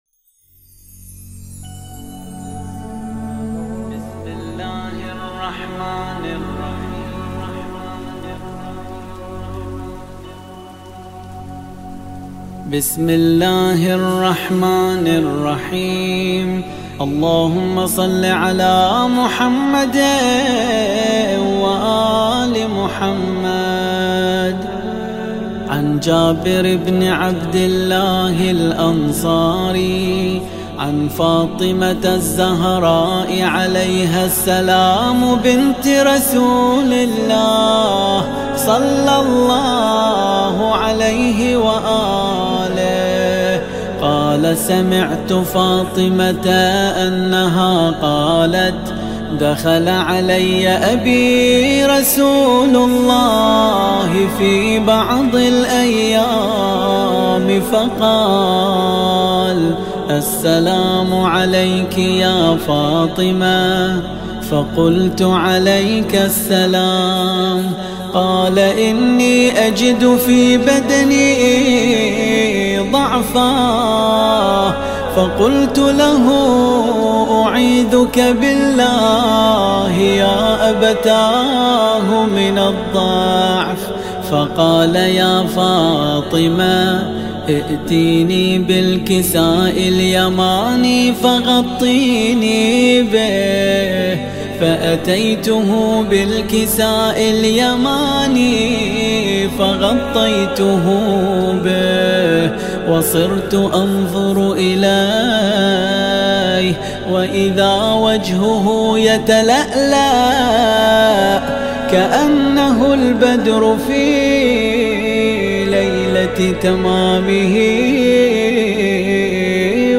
قرائت حدیث کساء با صدای دلنشین